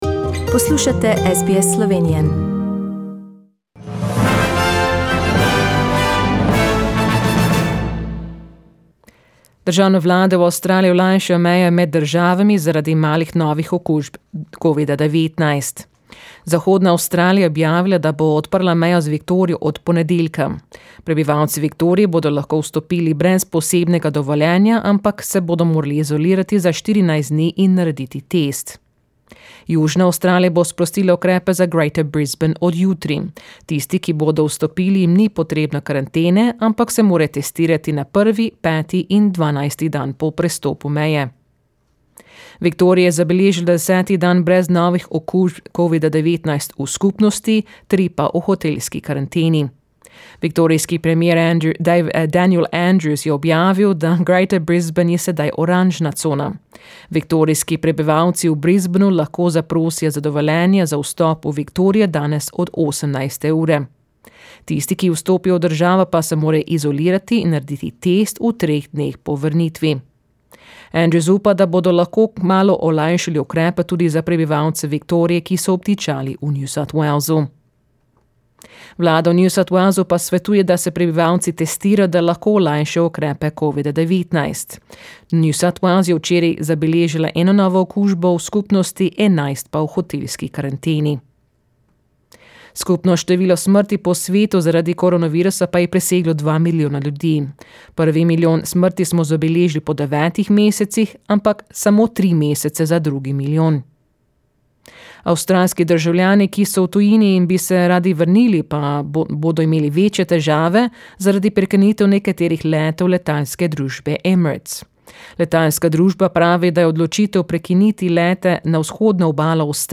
Today's news bulletin from the World, Slovenia and Australia in Slovenian.